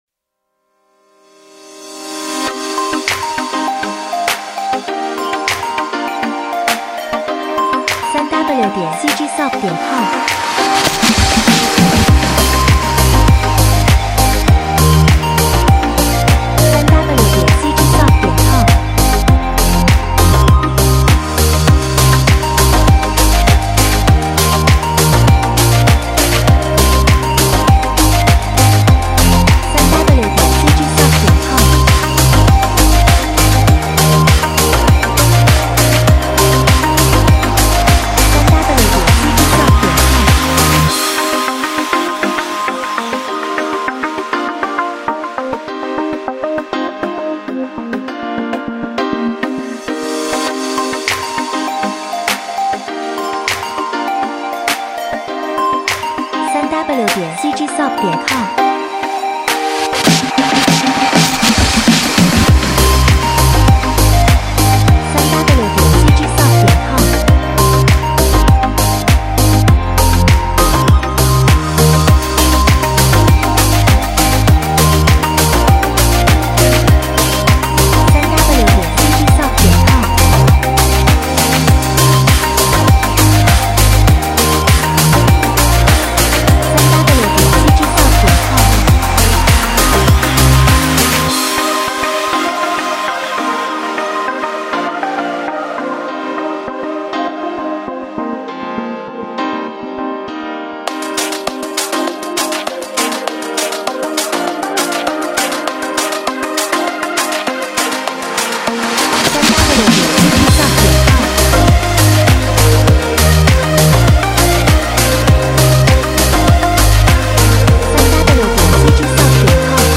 Upbeat Uplifting Energetic Dance Pop
欢快的音乐 – 是一个非常 积极 和 精力充沛 流行舞 和 党 轨道 它将带来 生活 你的项目！
采样率:16位立体声，44.1 kHz
时间（BPM）:100